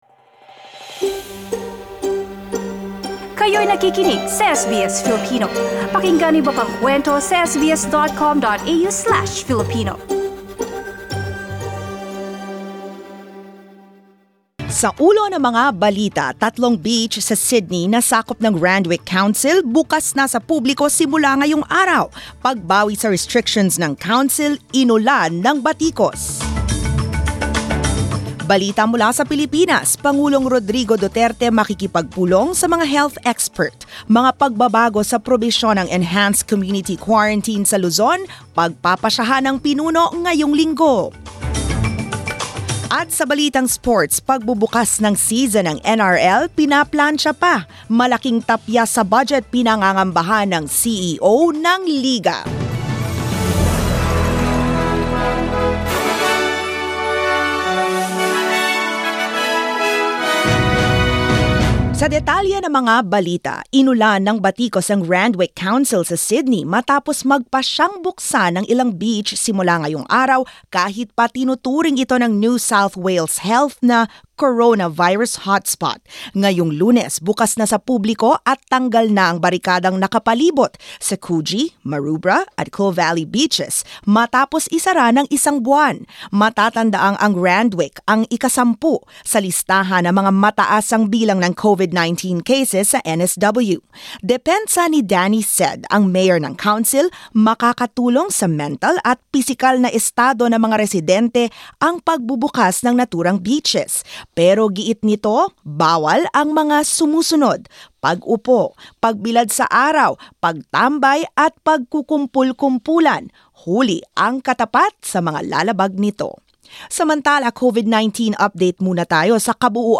SBS News in Filipino, Monday 20 April